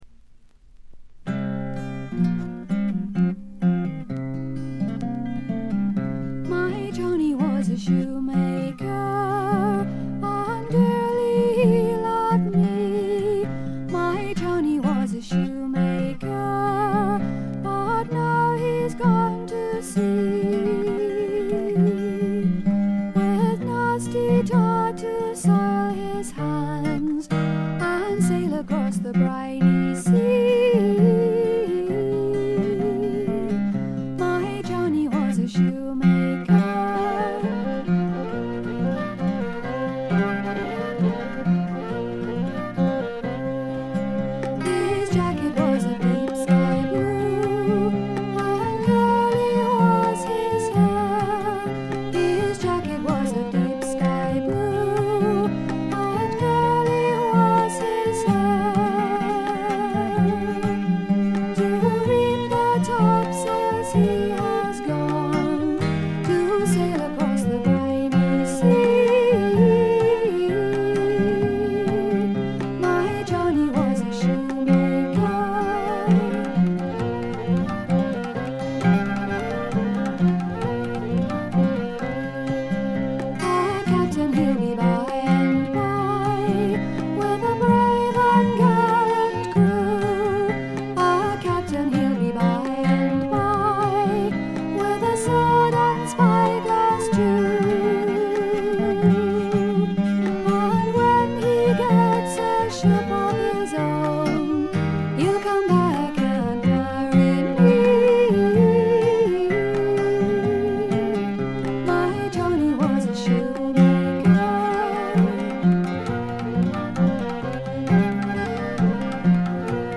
部分試聴ですがほとんどノイズ感無し。
また専任のタブラ奏者がいるのも驚きで、全編に鳴り響くタブラの音色が得も言われぬ独特の味わいを醸しだしています。
試聴曲は現品からの取り込み音源です。
vocals, flute, recorders, oboe, piccolo
fiddle, vocals
tabla, finger cymbals